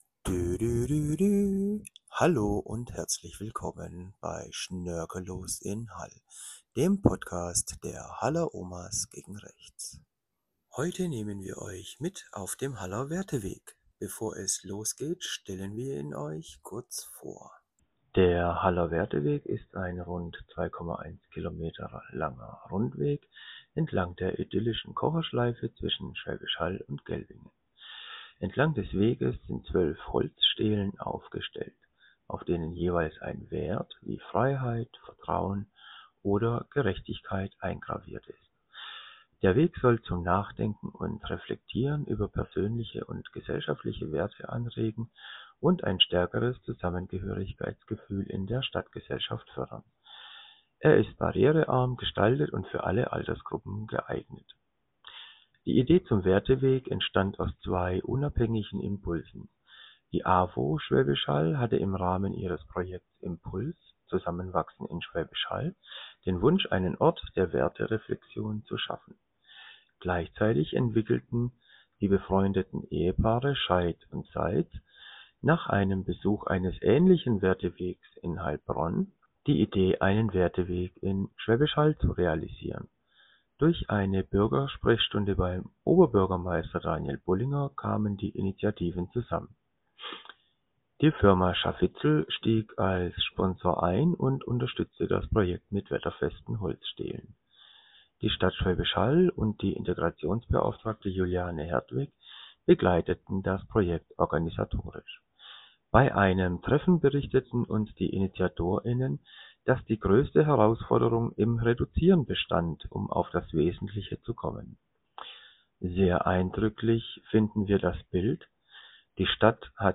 Wir nehmen Euch heute mit auf einen Spaziergang und eine Gedankenreise durch den Haller WerteWeg. Der WerteWeg wurde im Oktober 2024 eröffnet und lädt dazu ein über die Werte die unsere Gesellschaft verbindet nachzudenken.